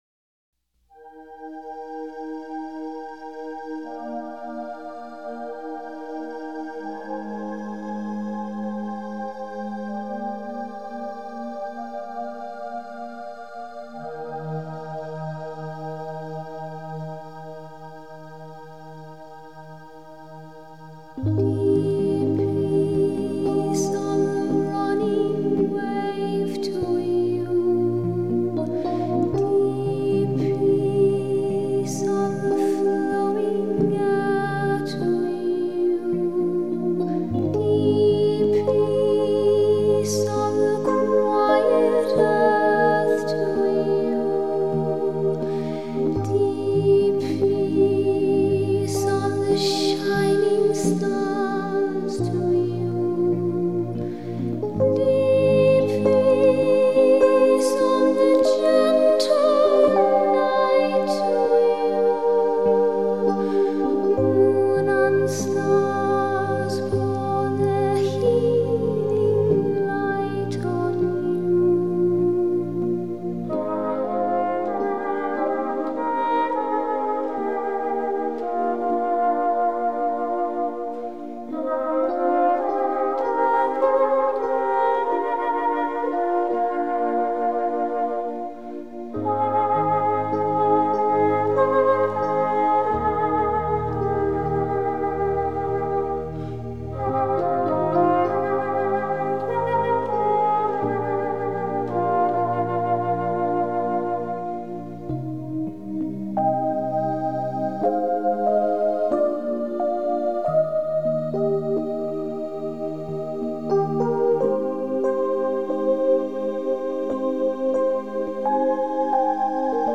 音乐+茶道＝心旷神怡。